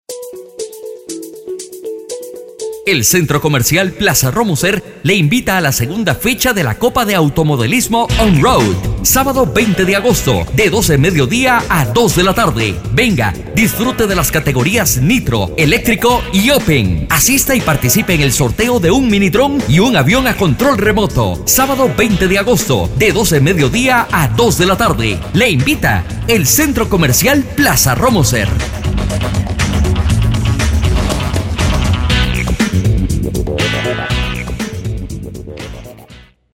Tengo la versatilidad de manejar español neutro, para video tutoriales, narraciones, comerciales, documentales, etc.
kastilisch
Sprechprobe: Industrie (Muttersprache):